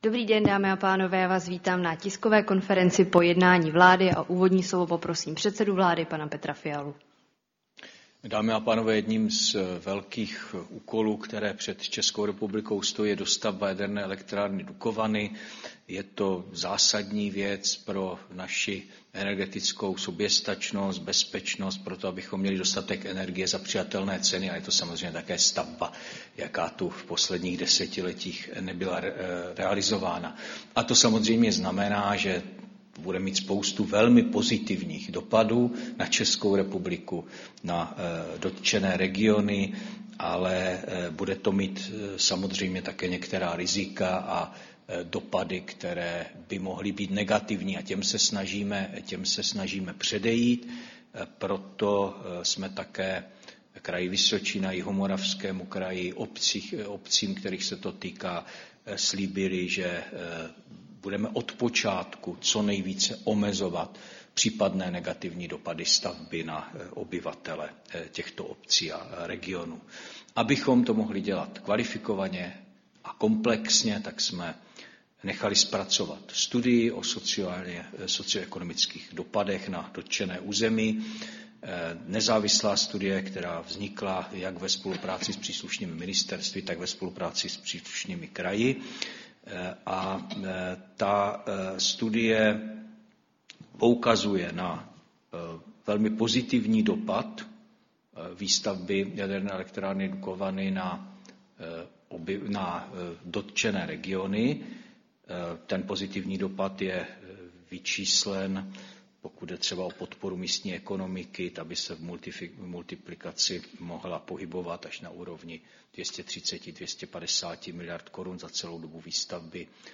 Tisková konference po jednání vlády, 15. ledna 2025